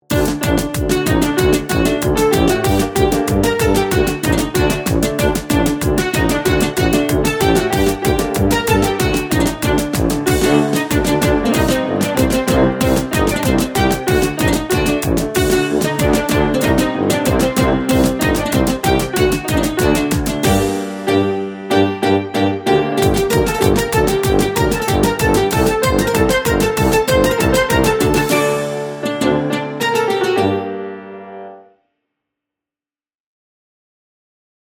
Artėjant žydų Naujiesiems metams, žydai verčia naują gyvenimo puslapį ir kviečia kaimynus padaryti tą patį – žengti į tolerantišką ateitį. Ta proga jie siunčia visiems dovaną – uždegančią žydišką melodiją, kuri gali virsti Jūsų telefono skambučiu.